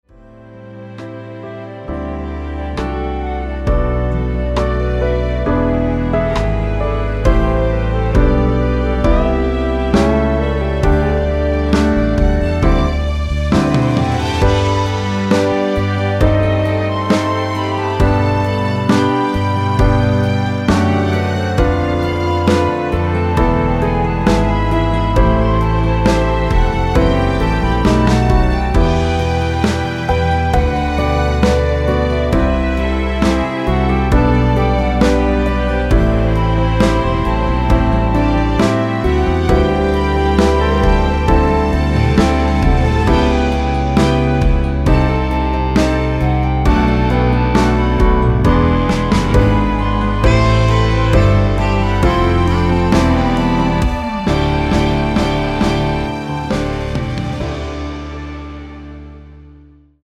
원키 멜로디 포함된 MR입니다.(미리듣기 확인)
앨범 | O.S.T
앞부분30초, 뒷부분30초씩 편집해서 올려 드리고 있습니다.